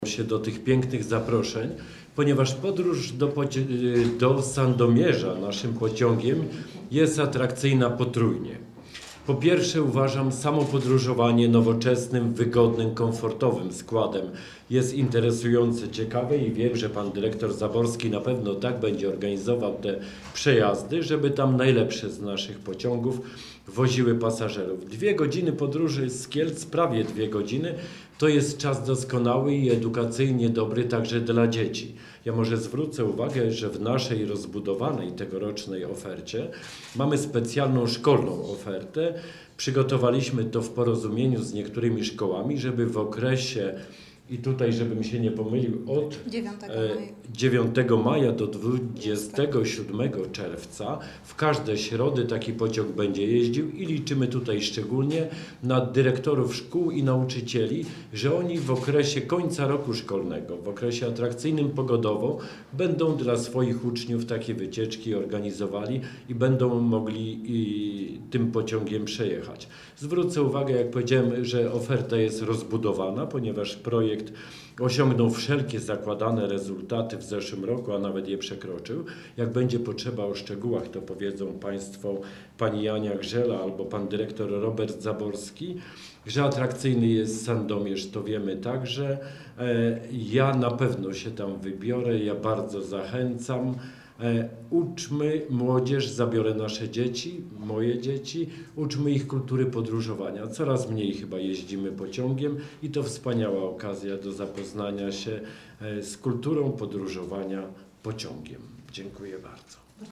Briefing prasowy na temat pociągu do Sandomierza
wicemarszałek-Jan-Maćkowiak.mp3